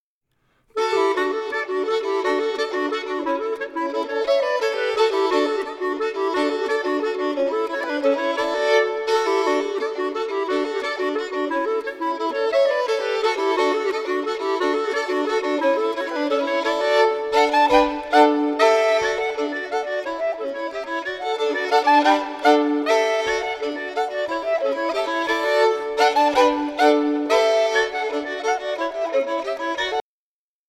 Vals
Inspelad: Harplinge kyrka - 1995